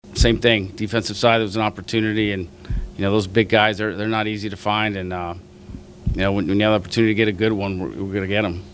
This year, they went for help on the defensive line.  Khan says he loves drafting big guys.